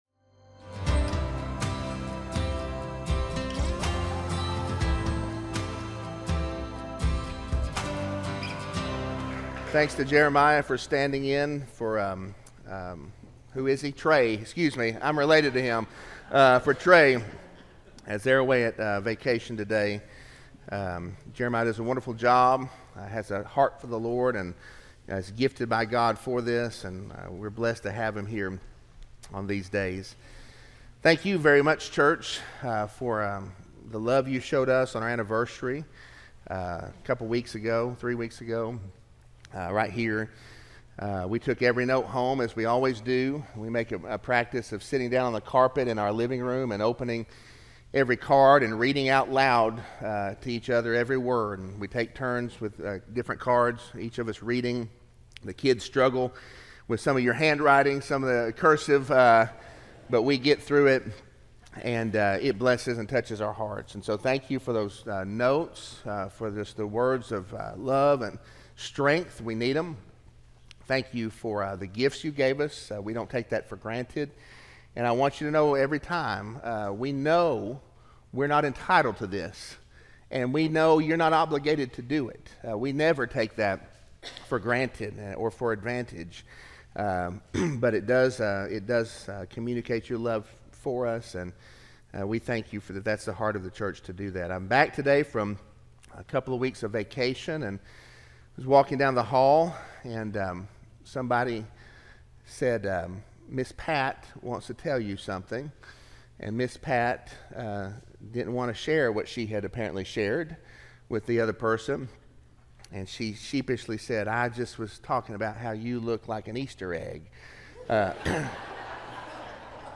Sermon-6-2-24-audio-from-video.mp3